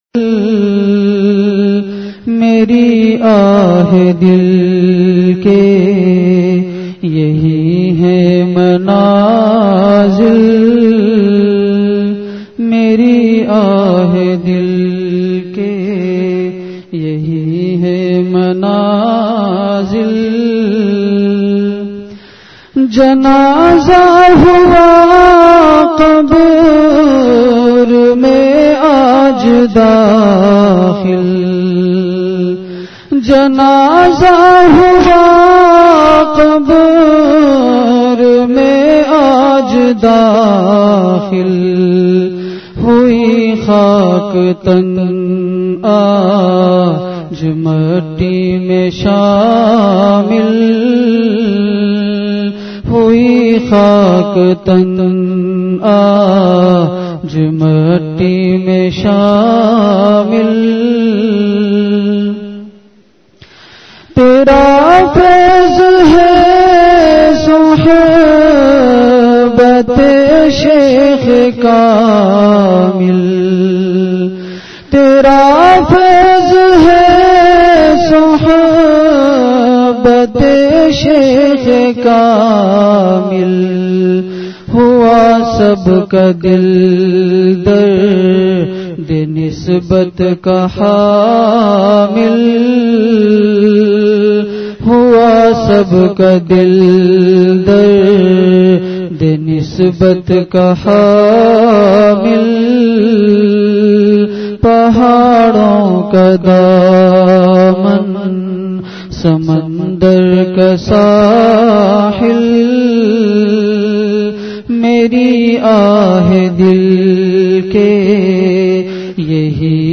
مسجد اختر گلستان جوہر بلاک ۱۲ میں فرمائی۔